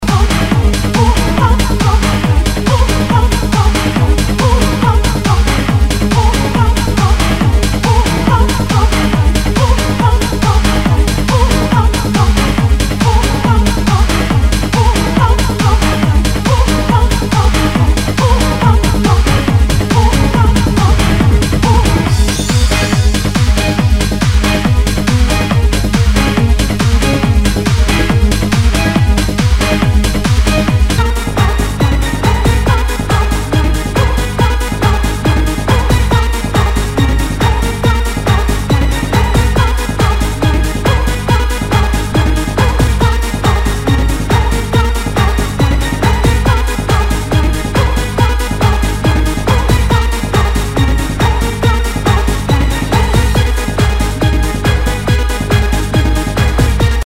HOUSE/TECHNO/ELECTRO
ナイス！ハード・ハウス！